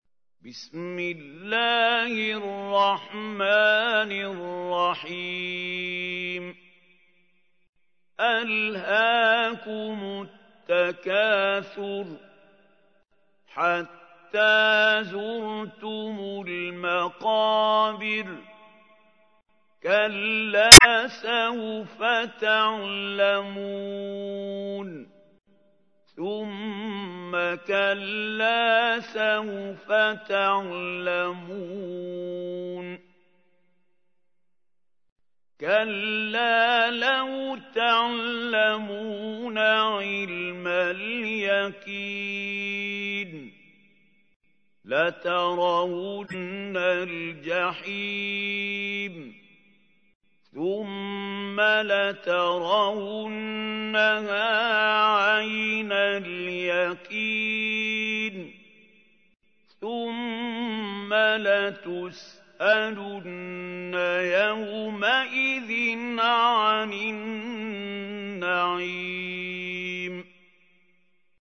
تحميل : 102. سورة التكاثر / القارئ محمود خليل الحصري / القرآن الكريم / موقع يا حسين